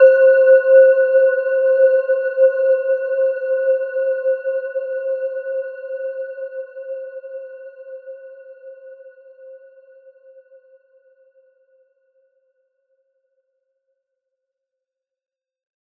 Gentle-Metallic-4-C5-f.wav